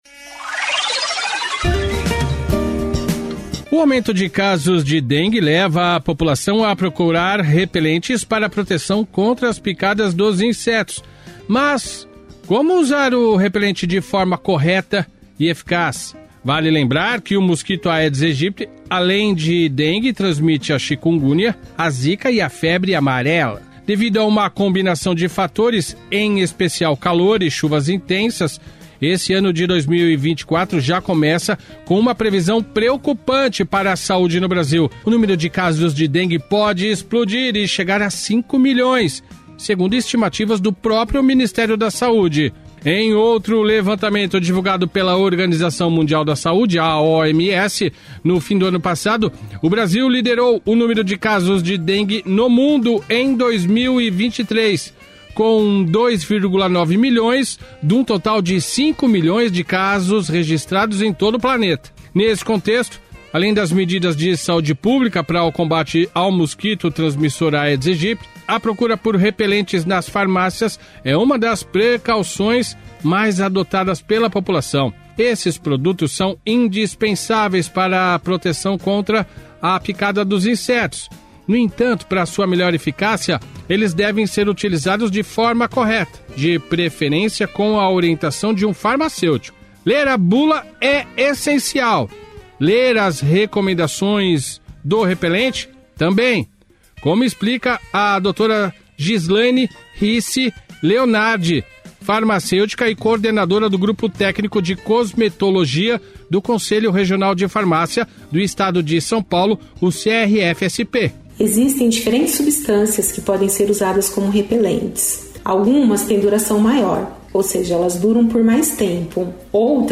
Rádio Metropolitana de Mogi das Cruzes